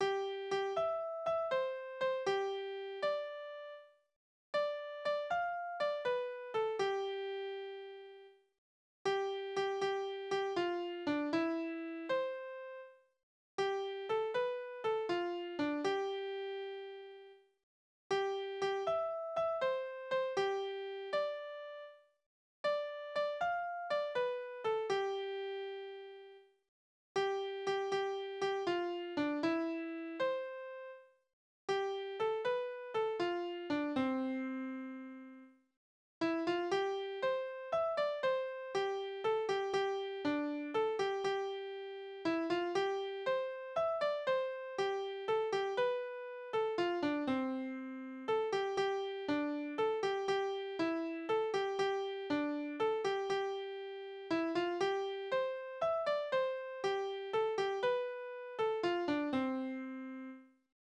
Balladen: Edelmann und Bauerndirn
Tonart: C-Dur
Taktart: 9/8, 3/4
Tonumfang: Oktave, Quarte